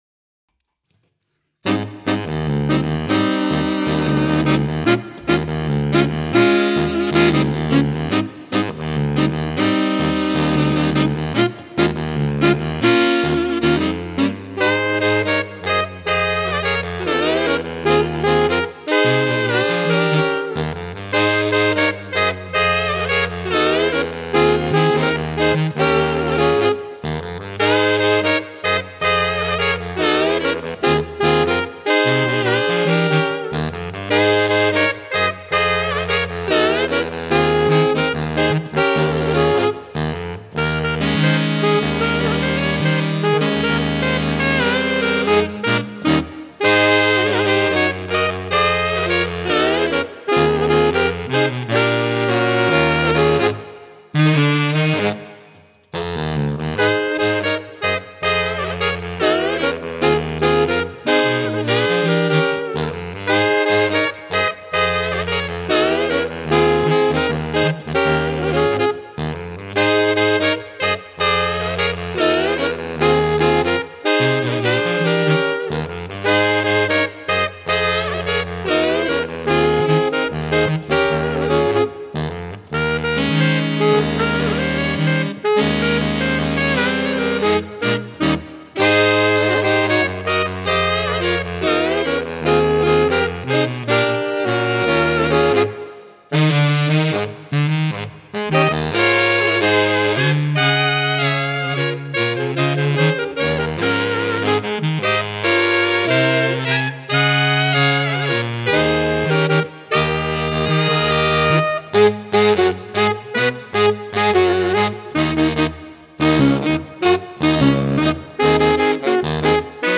is set in a funky Latin groove.
Ranges: Alto 2: F3. Tenor: C3. Baritone: B1
More Saxophone Quartet Music